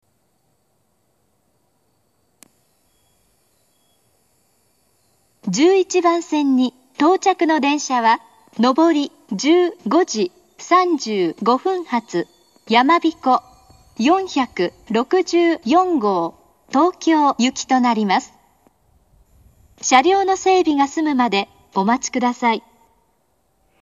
接近放送
到着放送
【接近放送〜発車予告放送】Maxやまびこ464